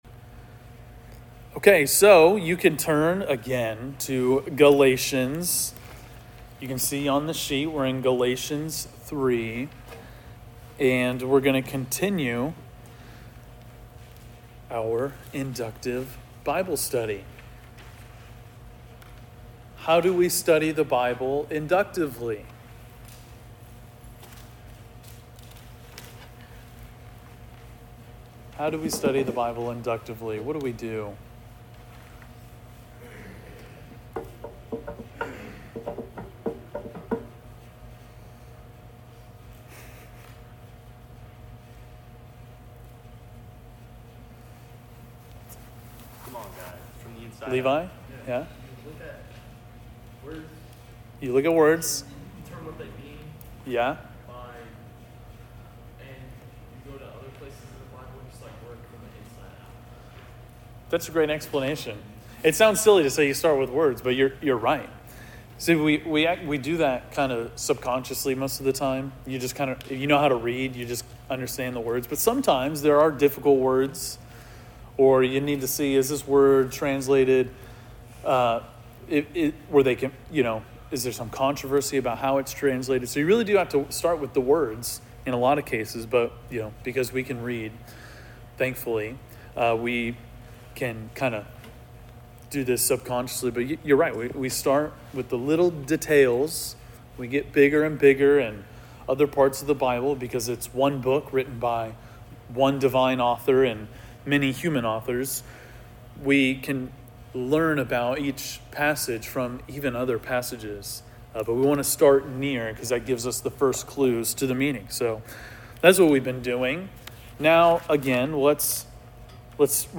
Galatians 3:19-25 (Inductive Bible Study)